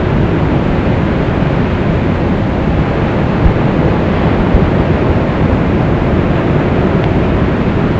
OCEAN SOUNDS
To hear the relaxing sound of the ocean.